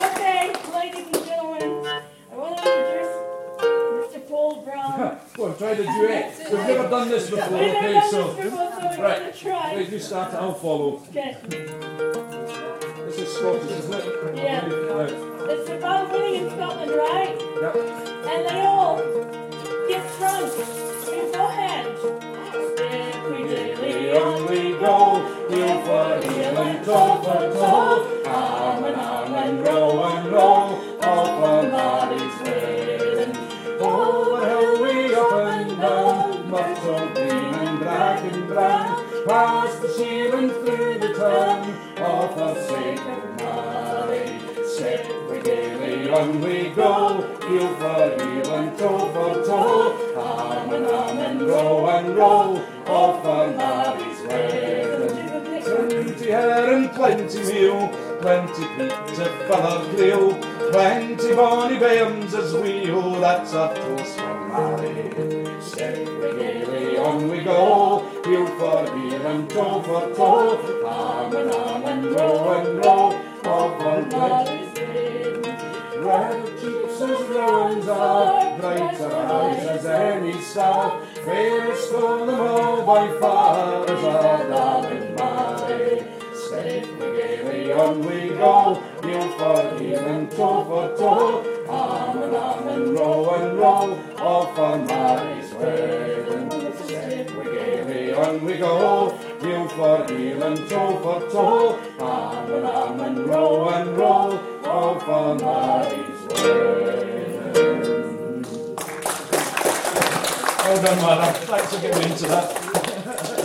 We sing "Marie's Wedding" and I play Harp.